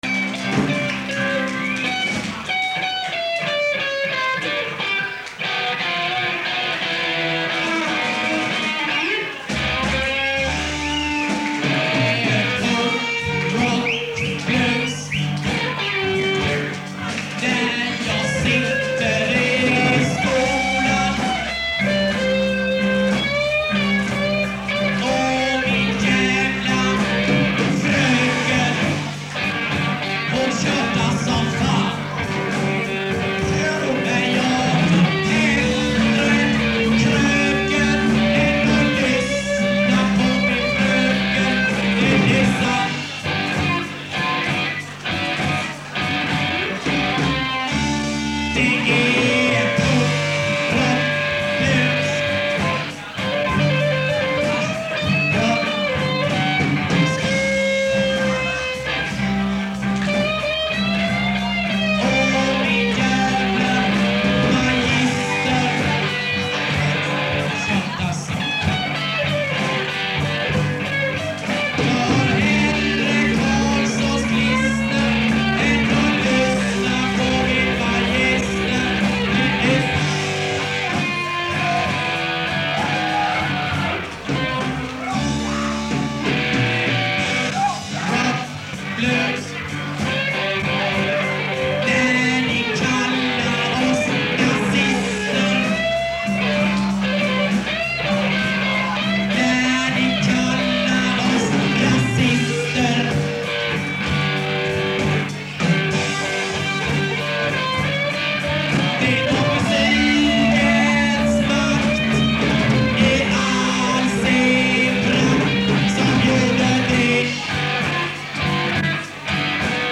Sprängkullen Frifomspunken dec -78